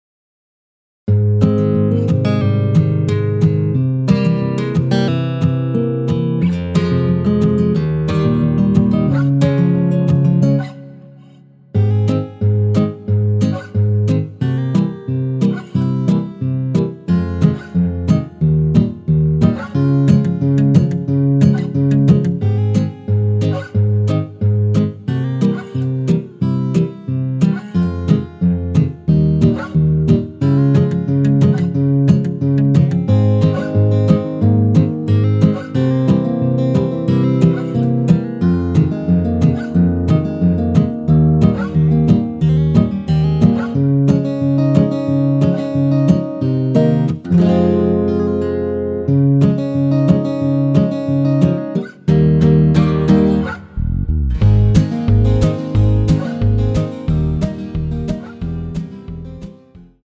원키에서(-3)내린 MR입니다.
Ab
앞부분30초, 뒷부분30초씩 편집해서 올려 드리고 있습니다.
중간에 음이 끈어지고 다시 나오는 이유는